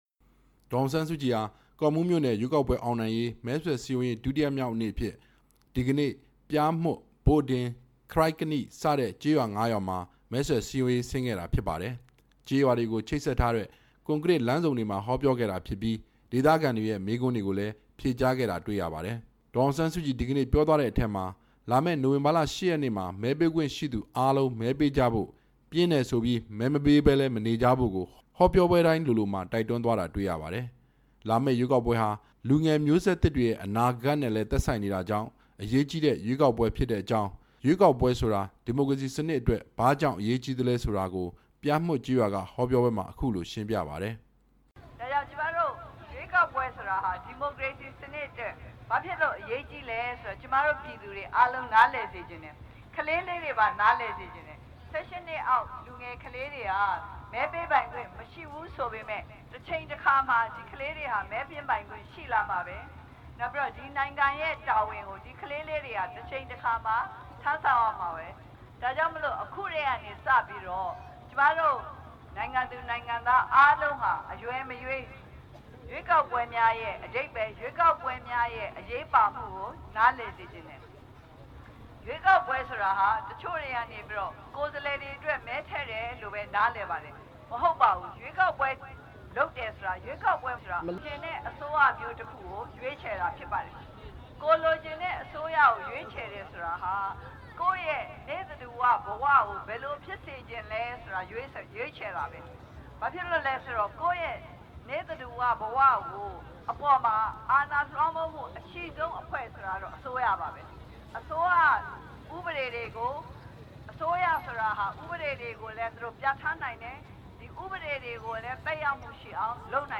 ဒီကနေ့ ရန်ကုန်တိုင်း ကော့မှူးမြို့နယ် ခရိုက်ကနိကျေးရွာ မဲဆွယ်ဟောပြောပွဲမှာ ပြောကြားခဲ့တာဖြစ်ပါတယ်။